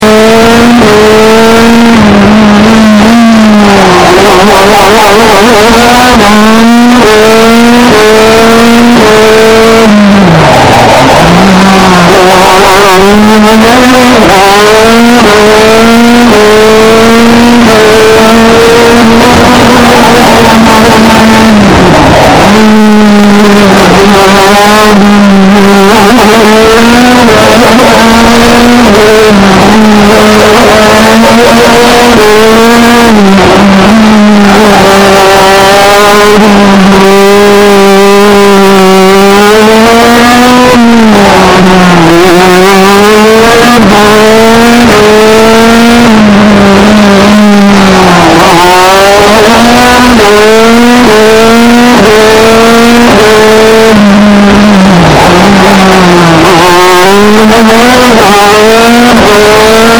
Kart 125 Rotax
Wenn du genau hinhörst kannst du im Hintergrund das kreischen der heissen Qualifying-Slicks am Asphalt hören.
teesdorf1.mp3